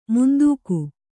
♪ mundūku